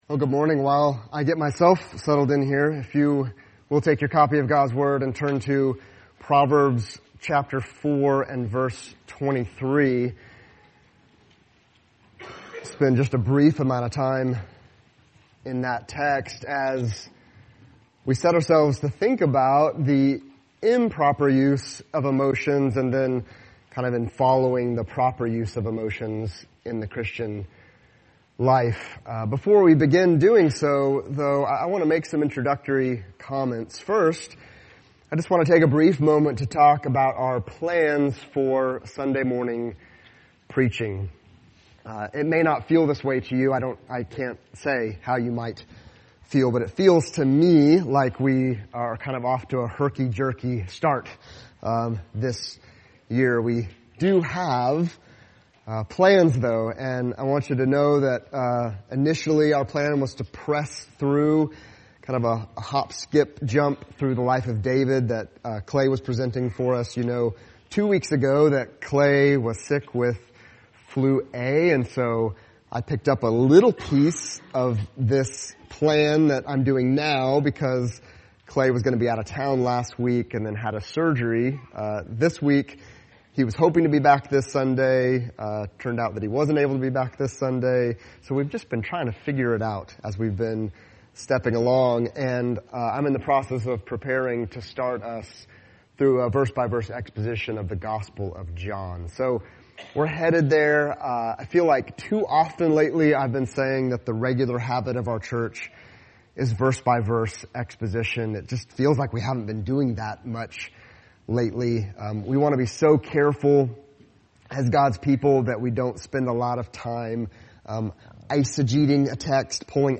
Sermons – Christ Family Church podcast